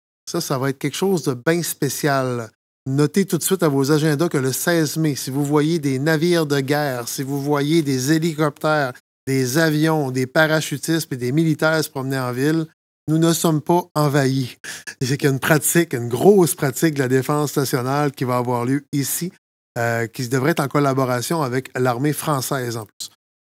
Le maire de Gaspé, Daniel Côté, veut prévenir la population :